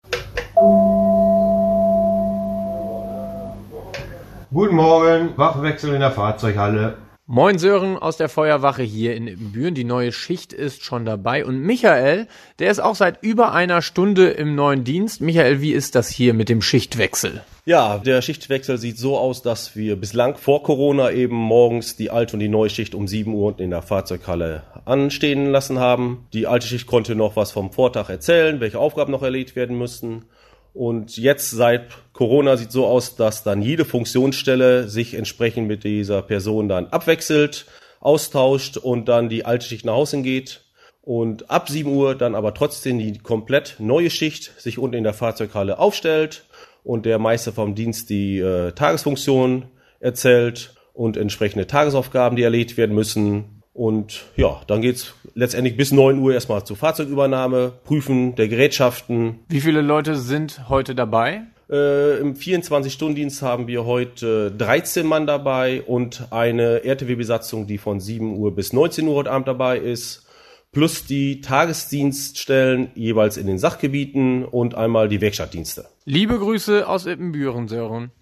In unserer RADIO RST-Frühschicht treffen wir dich da, wo du morgens arbeitest und sprechen mit dir über aktuelle Themen.
Feuerwache Ibbenbüren